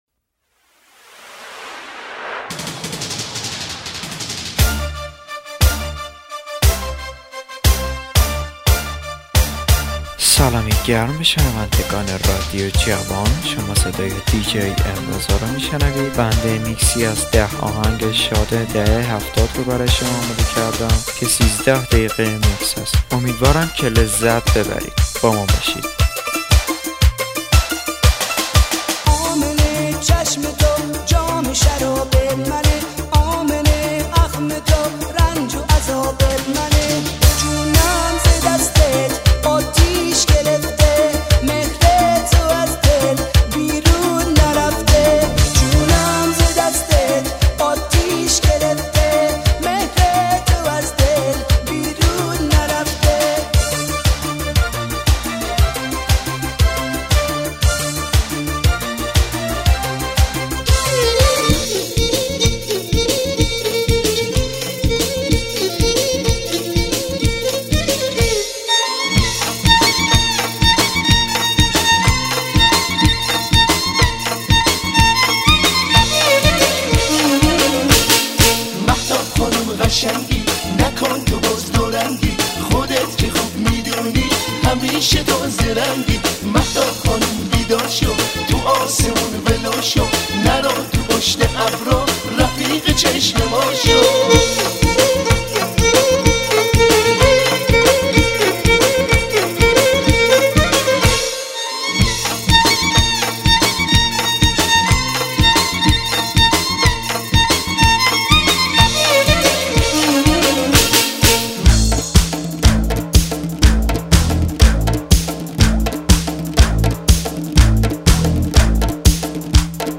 دانلود ریمیکس شاد قدیمی دهه هفتاد | مخصوص جشن ها و ماشین|